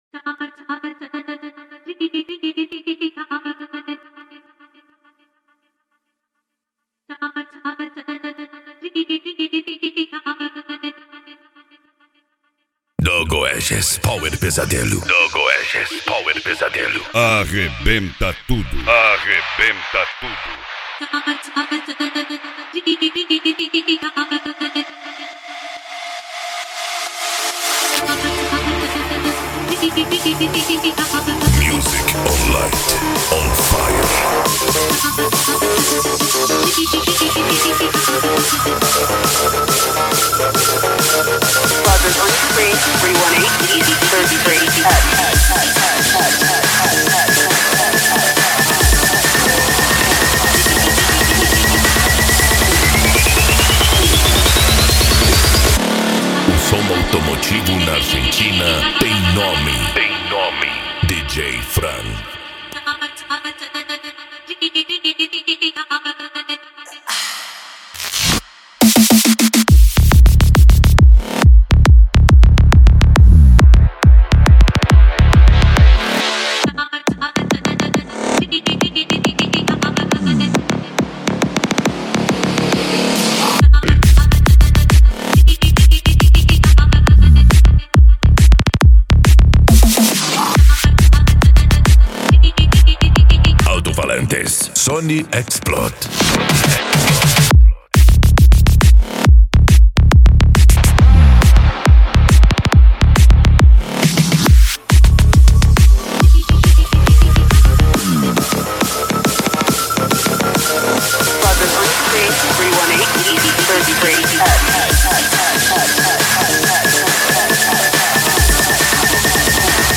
Musica Electronica
Psy Trance
Remix
Techno Music
Trance Music